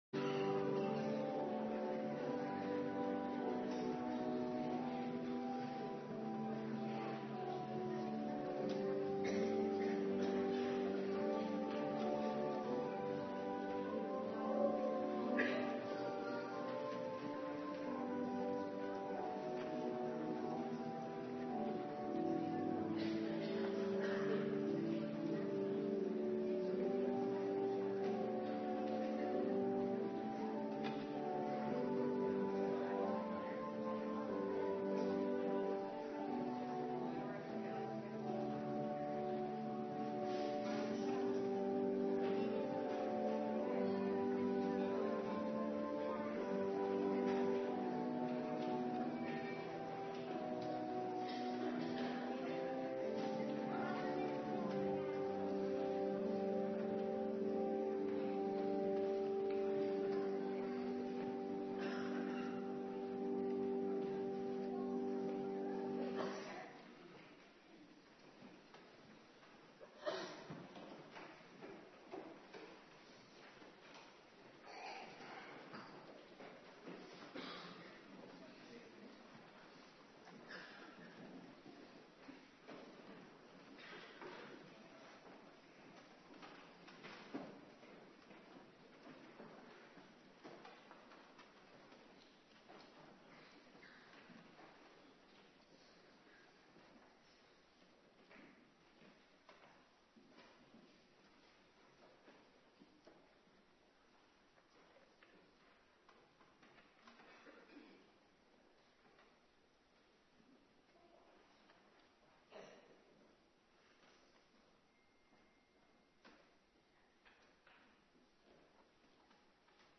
Morgendienst
09:30 t/m 11:00 Locatie: Hervormde Gemeente Waarder Agenda: Kerkdiensten Terugluisteren Handelingen 1:15-26